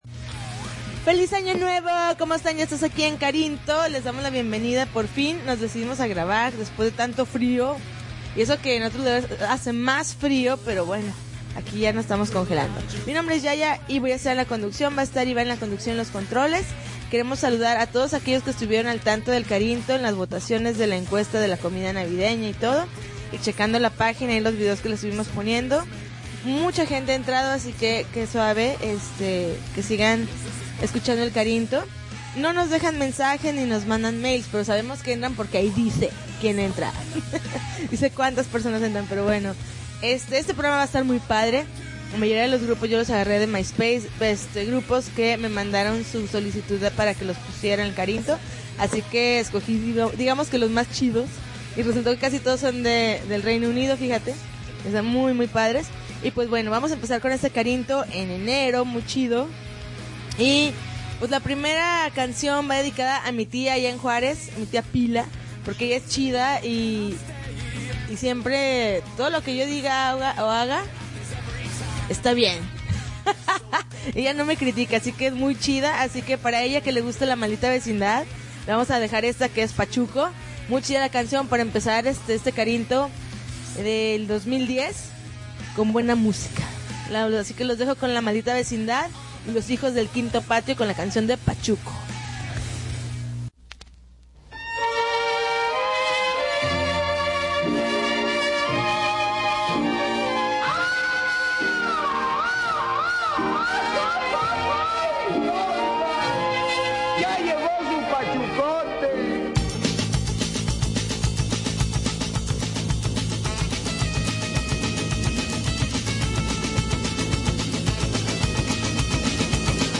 January 10, 2010Podcast, Punk Rock Alternativo